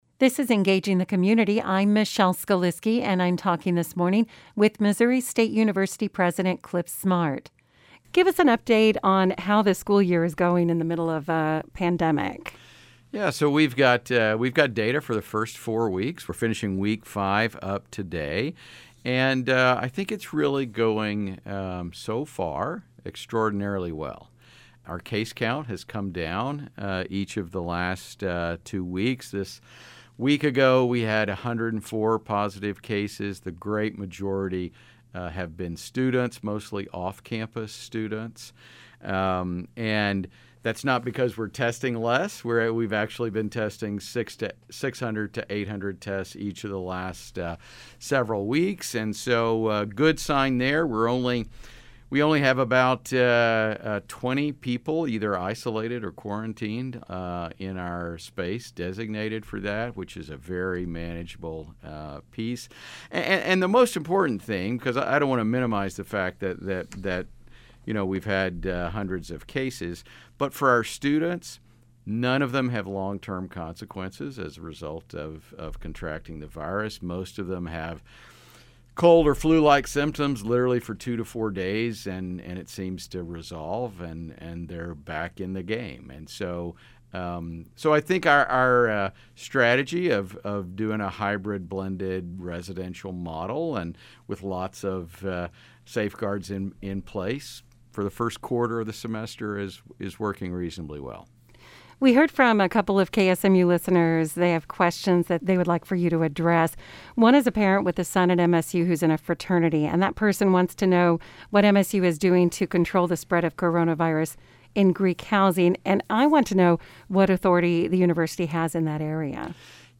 Interview with MSU president, Clif Smart